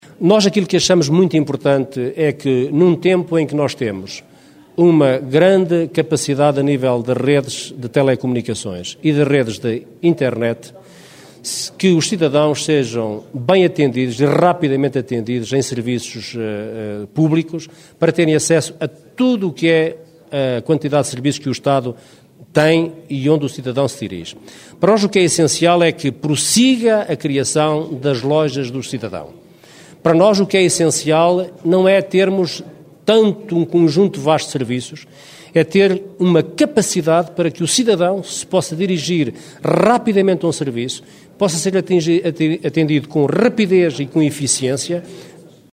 Declarações de Adão Silva, na apresentação oficial da lista pela coligação PSD/CDS-PP pelo distrito de Bragança, que aconteceu em Alfândega na Fé.